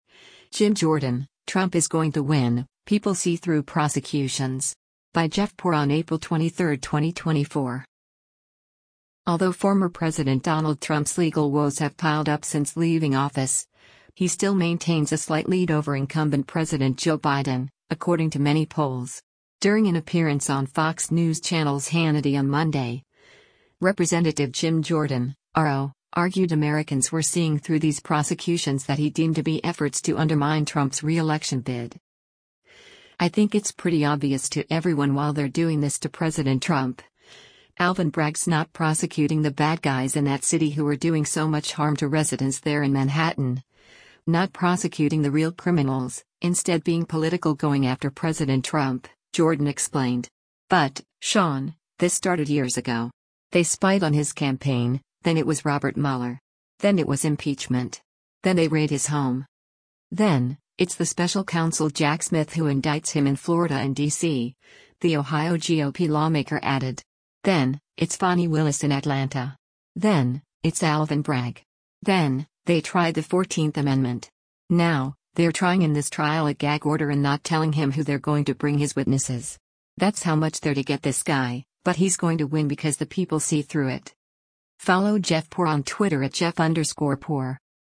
During an appearance on Fox News Channel’s “Hannity” on Monday, Rep. Jim Jordan (R-OH) argued Americans were seeing through these prosecutions that he deemed to be efforts to undermine Trump’s reelection bid.